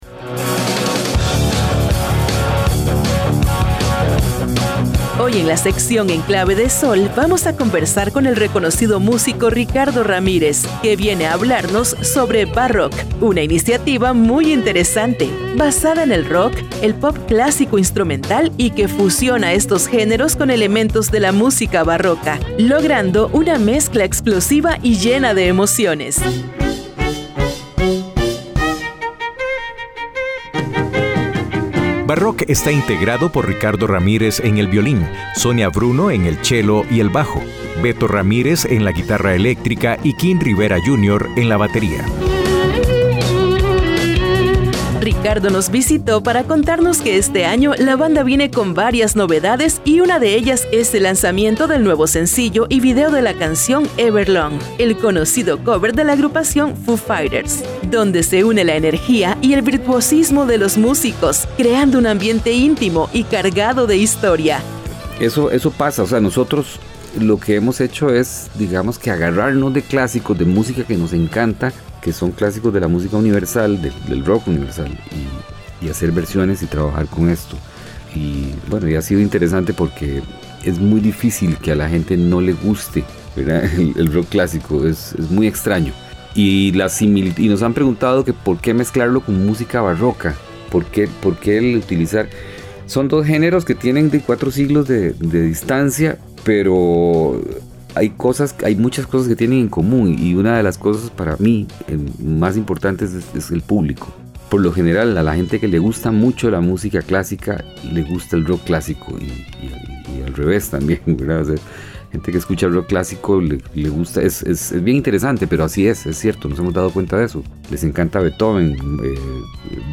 Hoy en la sección, En Clave de Sol, vamos a conversar con reconocido músico